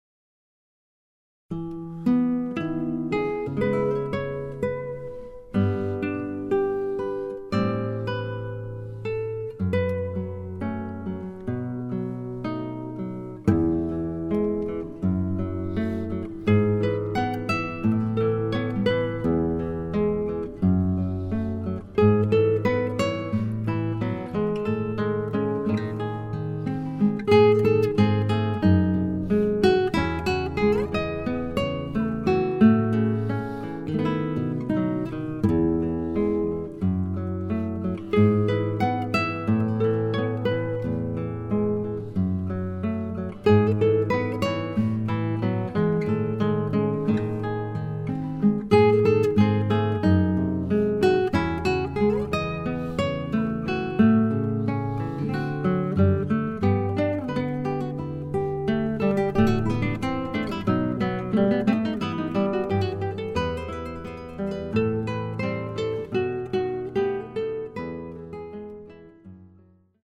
DÚO DE GUITARRAS